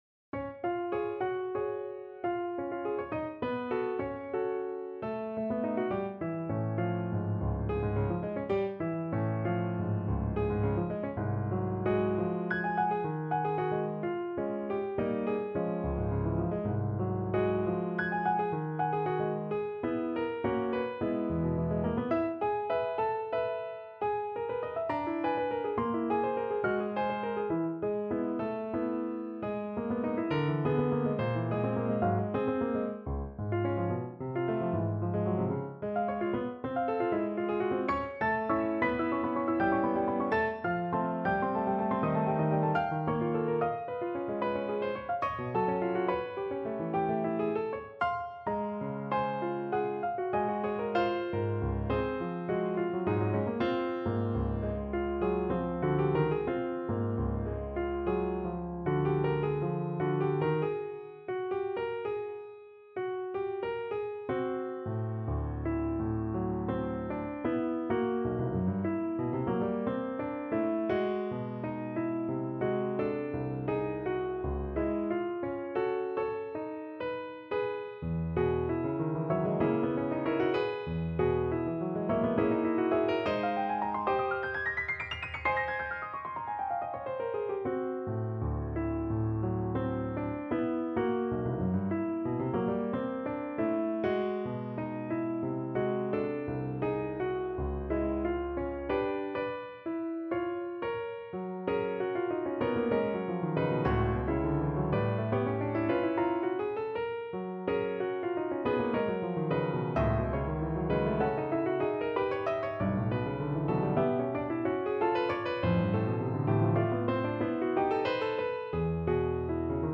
No parts available for this pieces as it is for solo piano.
His music is characterized by unusual harmonies and modulations
Piano  (View more Intermediate Piano Music)
Classical (View more Classical Piano Music)